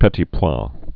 (pĕtē pwä, pə-tē)